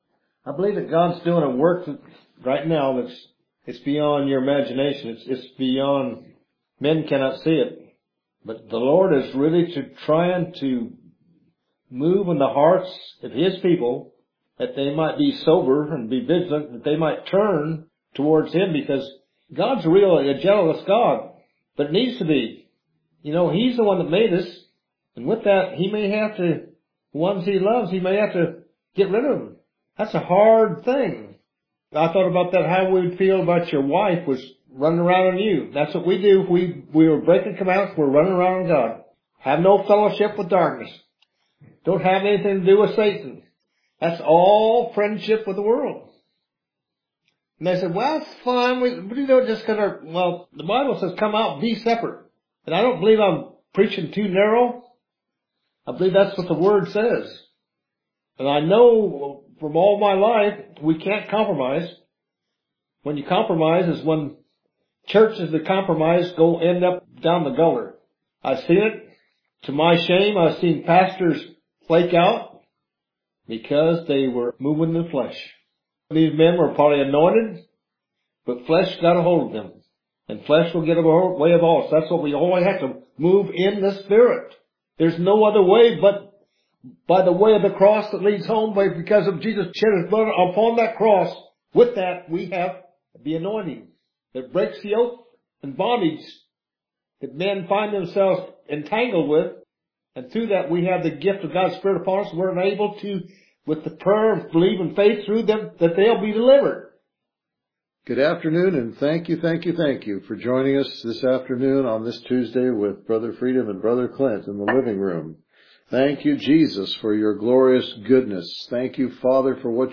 Scripture Reading: Titus 2:1-15